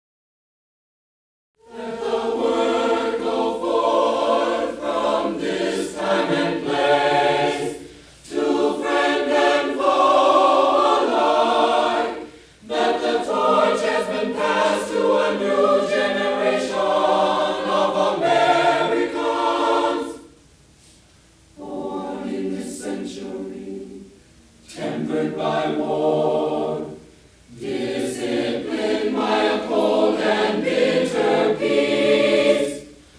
Highland Park, MI, High School Concert Choirs, 1954-1969